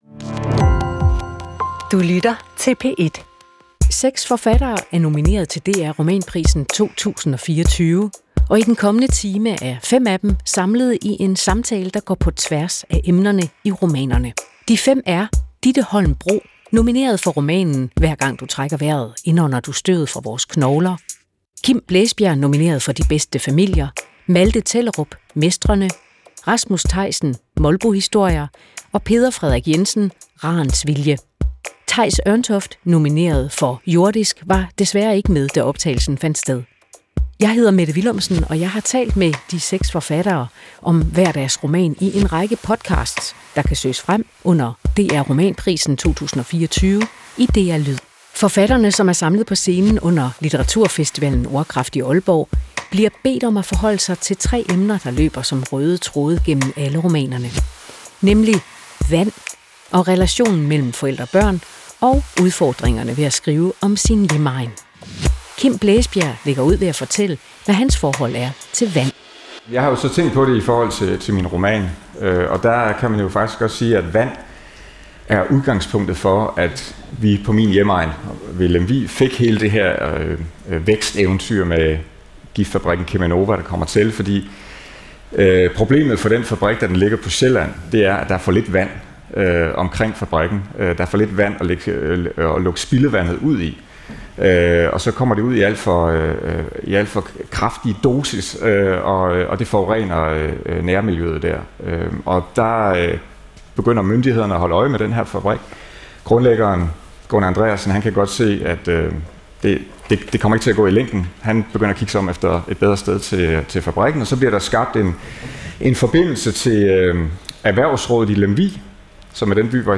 Hav, regn, storm, sved og opvaskevand. Forfatterne, der er nomineret til DR Romanprisen 2024, mødes i en samtale om det vand, der omgiver os alle, og som de hver især skriver om.